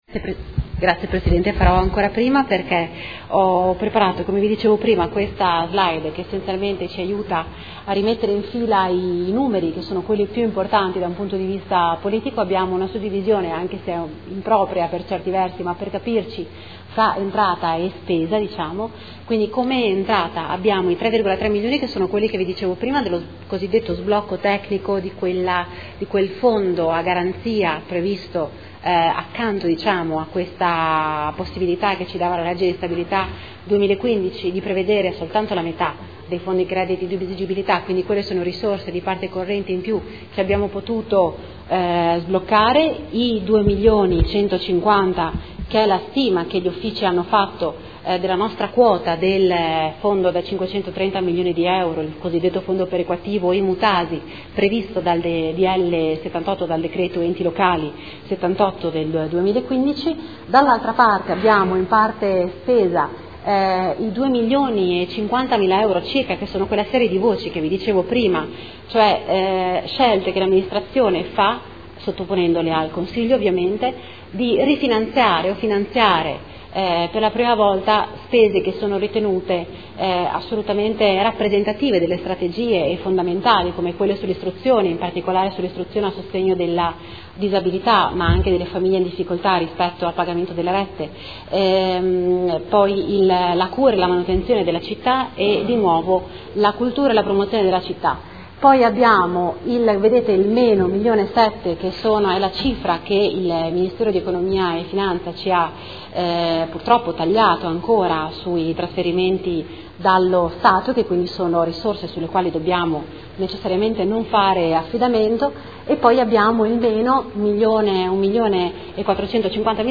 Seduta del 27/07/2015. Conclude la presentazione delle 3 delibere